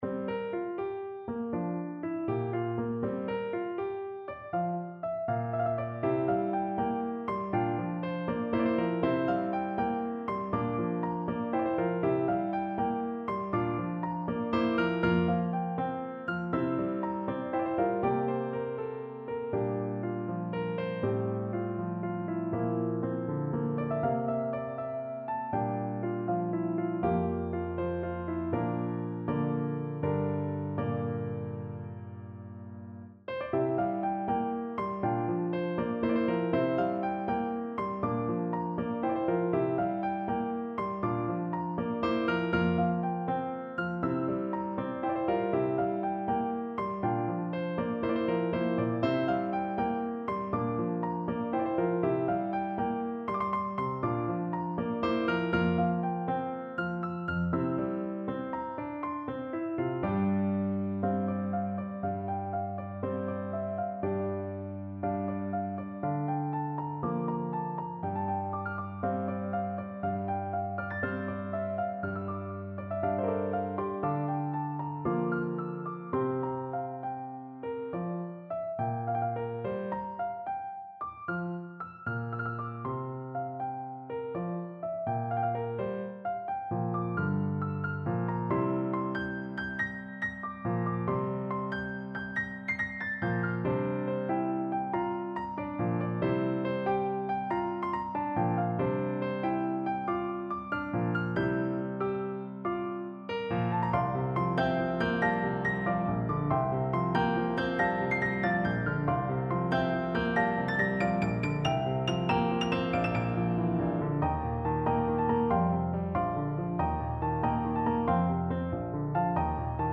PERSONAL 0 2,763 Ramble 26 8 years ago 1,164 words Creativity Music I've composed some new music for the first time in ages - a long piano 'ramble' - so I want to show and talk a bit about that!
My music is soft and idiosyncratic, different in structure and feel to the types of music that tend to become popular, and that distance from what's familiar makes me assume that other people will perceive it as alien and unappealing - boring, perhaps - for that reason.